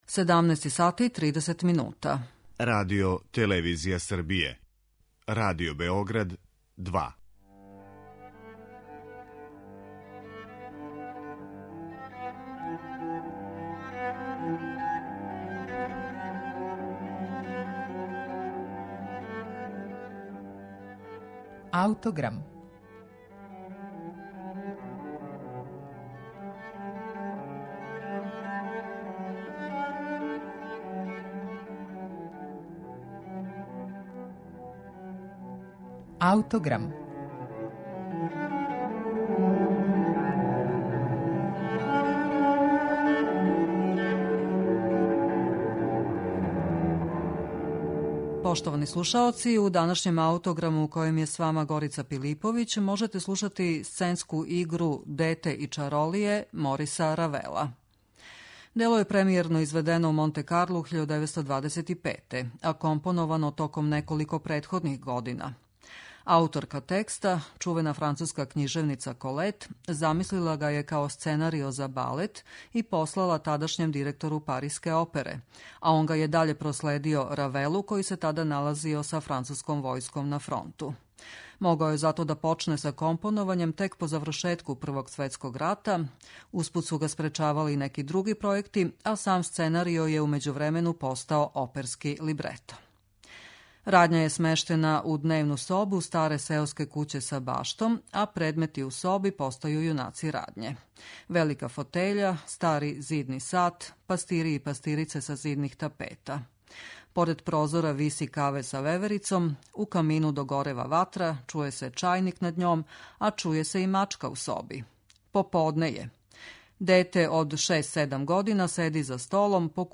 Сценска игра 'Дете и чаролије', Мориса Равела